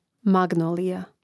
màgnōlija magnolija